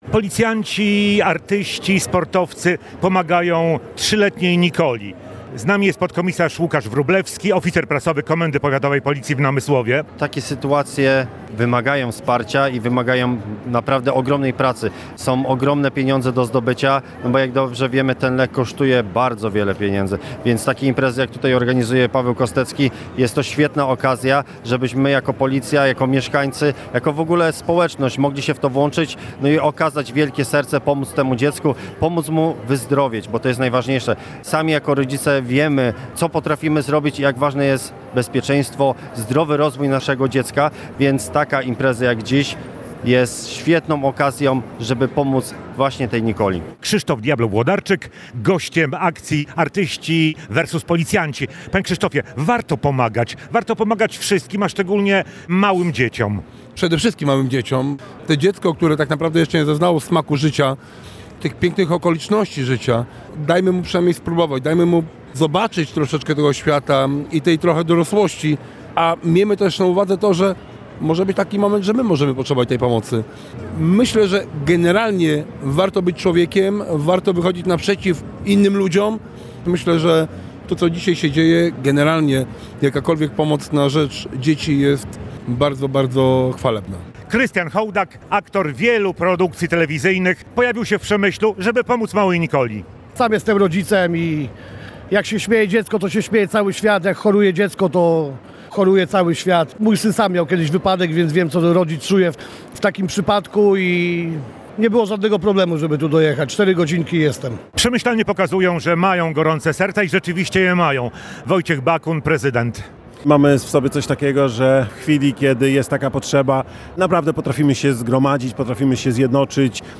Relacje reporterskie • Przemyślanie włączyli się do akcji ratowania życia i zdrowia 3-letniej córki małopolskiego policjanta.